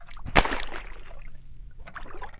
Water_03.wav